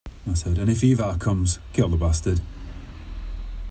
I’m also listening again to The Lords of the North audiobook, as I work my way through the The Last Kingdom series by Bernard Cornwall. Going between the two audio performances by Richard Armitage, I am struck by the vast difference in the way he pronounces the word “bastard” — in 2007 with an accent from the North of England and in 2018 with a growly North American accent.